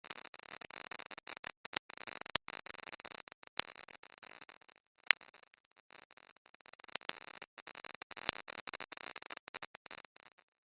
Tv Static Light
TV Static Light.mp3